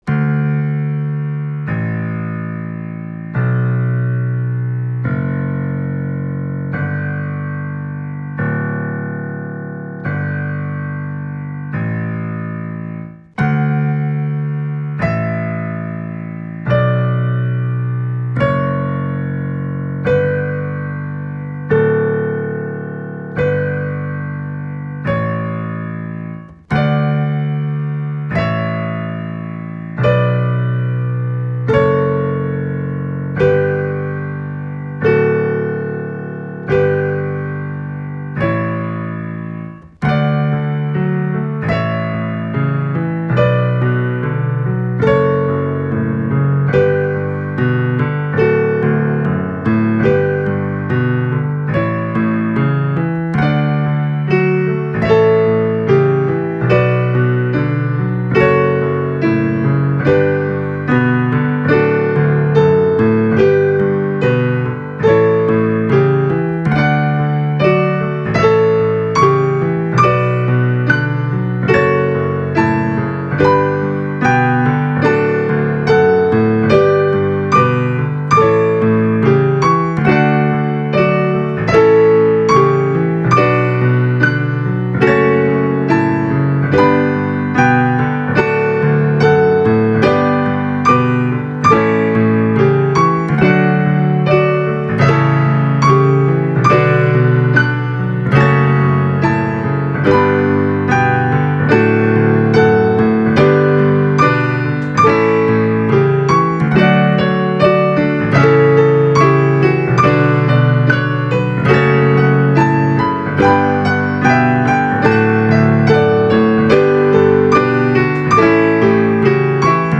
quick and easy piano ensembles for small to large groups